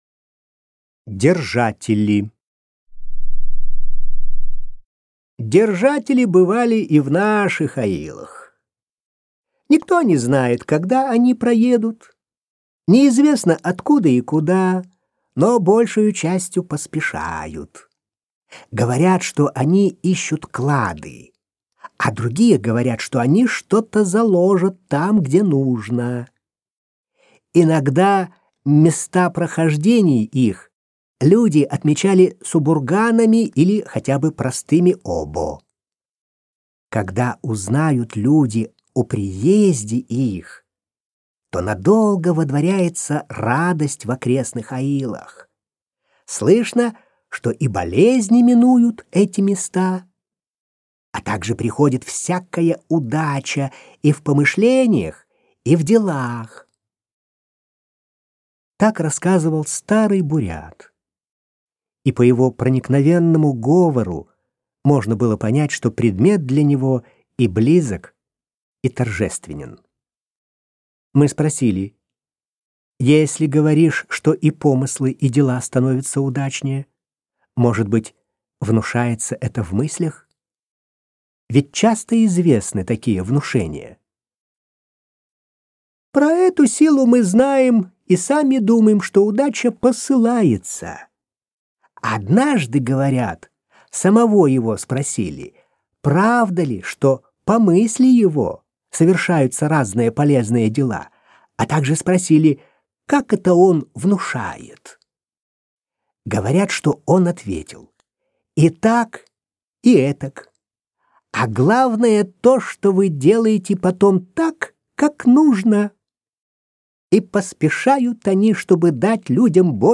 Аудиокнига Шамбала. Легенды и сказки | Библиотека аудиокниг